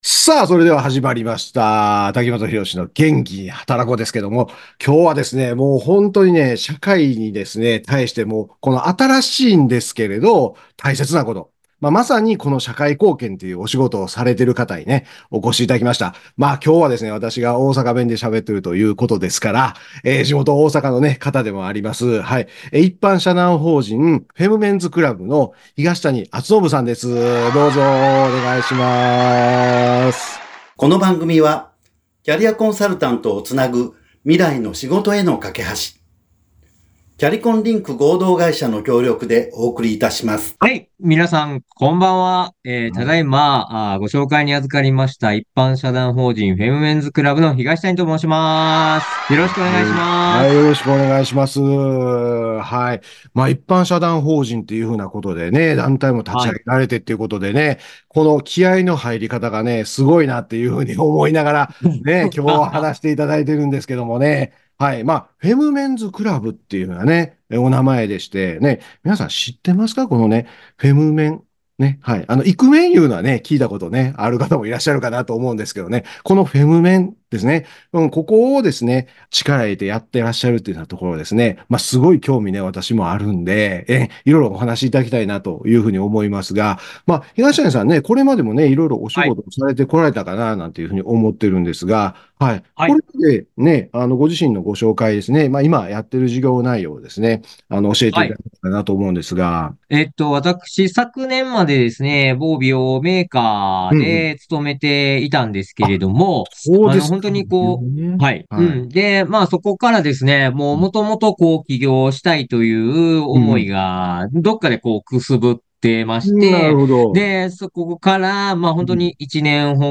FM79.7京都三条ラジオカフェ2024年3月14日放送分です。